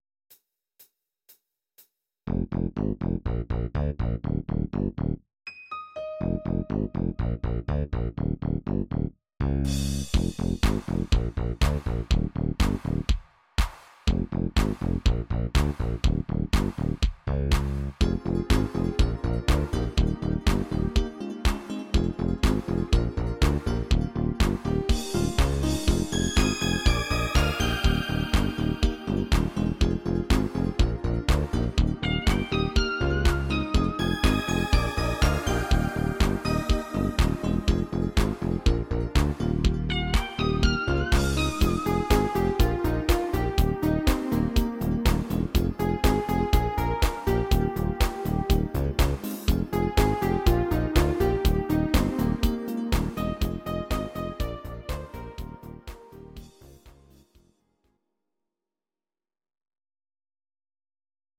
Audio Recordings based on Midi-files
Ital/French/Span, 1980s